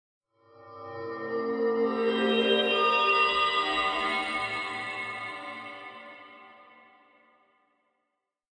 Minecraft Version Minecraft Version 1.21.5 Latest Release | Latest Snapshot 1.21.5 / assets / minecraft / sounds / ambient / nether / warped_forest / here1.ogg Compare With Compare With Latest Release | Latest Snapshot